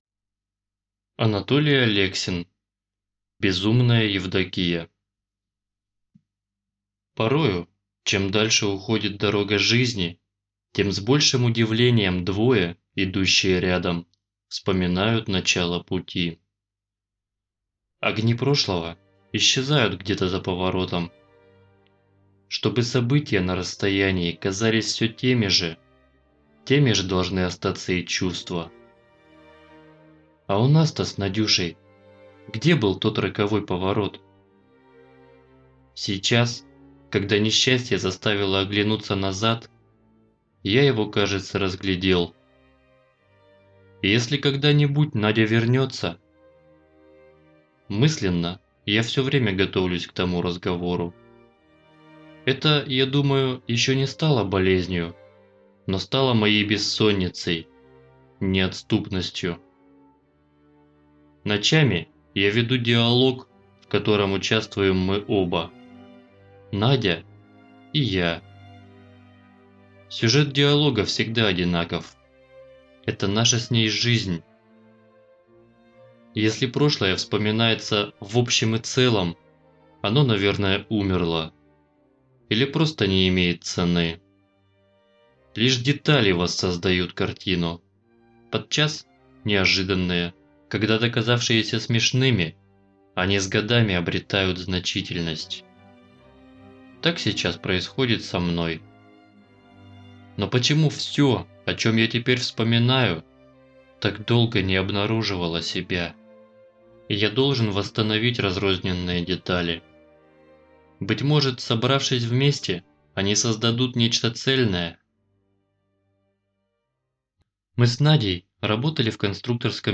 Безумная Евдокия - аудио повесть Алексина - слушать онлайн